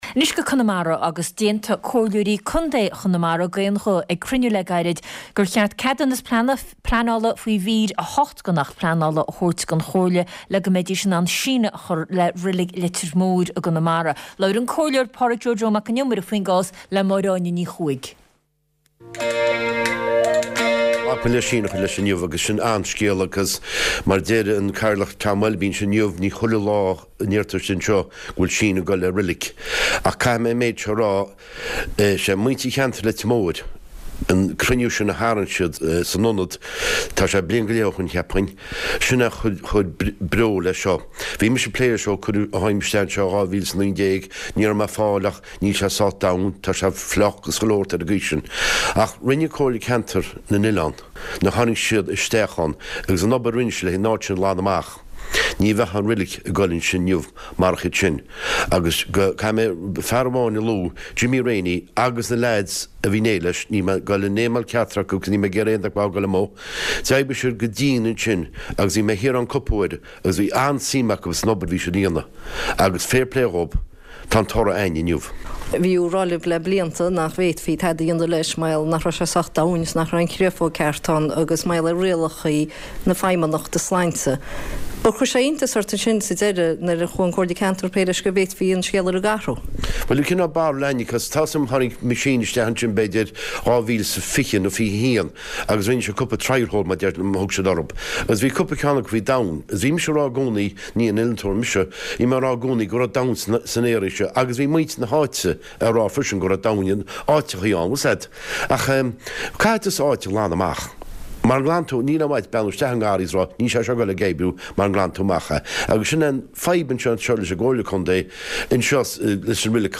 Clár cúrsaí reatha agus nuachta náisiúnta, idirnáisiúnta agus áitiúil le tuairisc spóirt agus tuairisc ar na nuachtáin.